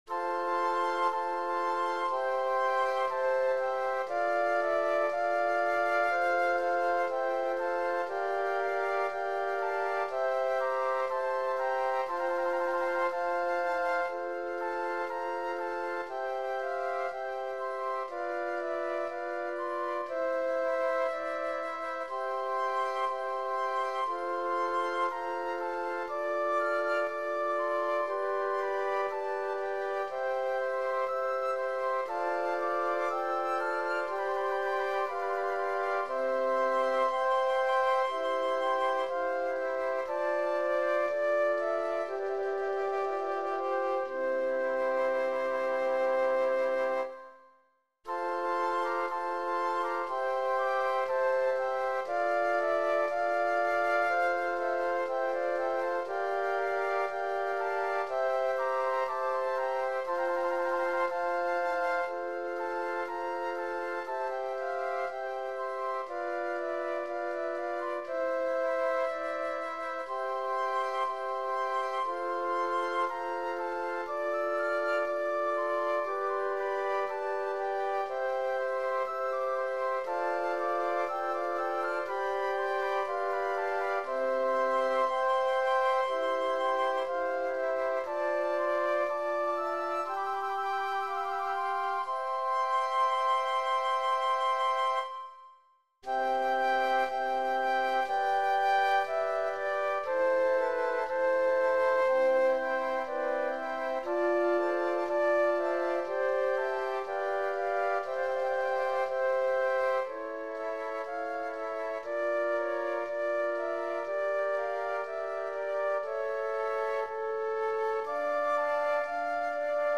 Voicing: Flute Sextet